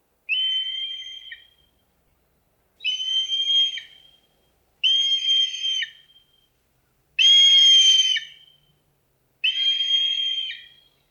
「日本の鳥百科」クマタカの紹介です（鳴き声あり）。日本から中国南部、ヒマラヤなどに分布していて、日本ではほぼ全国で繁殖しています。